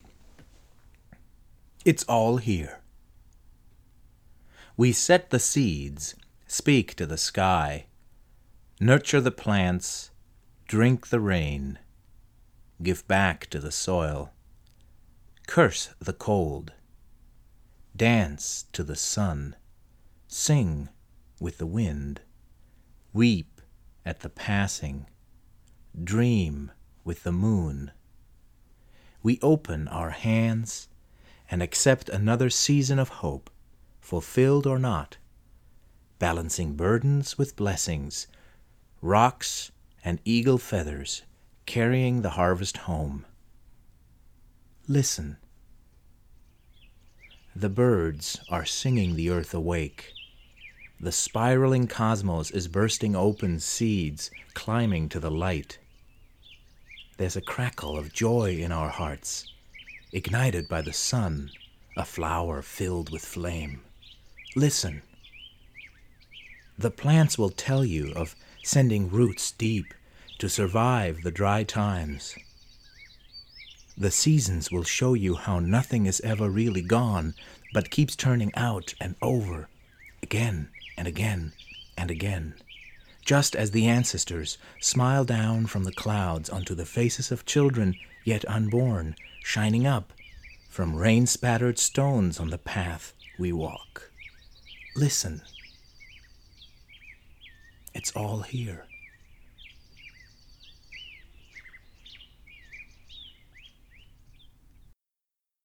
I recorded this poem some years ago for a radio program in Kansas City, USA.